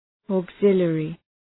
Shkrimi fonetik {ɔ:g’zıljərı}